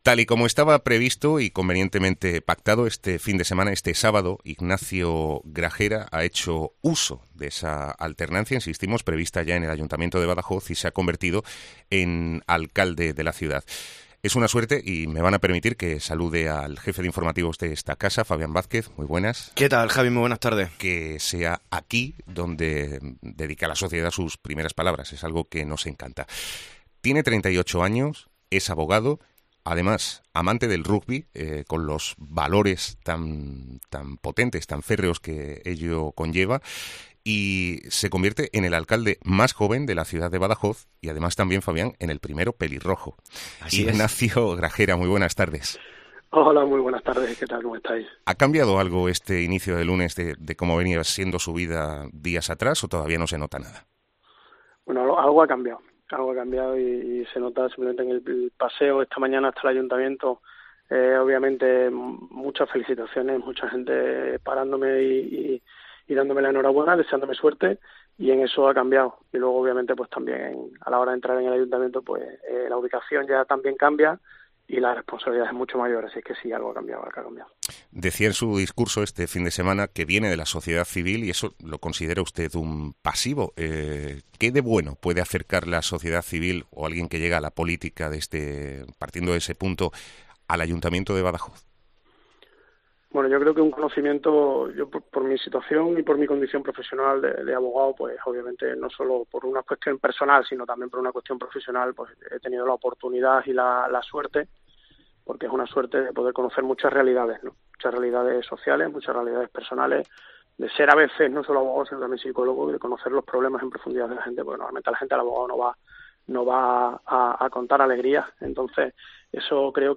El nuevo alcalde de Badajoz ha concendido a COPE su primera entrevista a los medios de comunicación tras su investidura del pasado sábado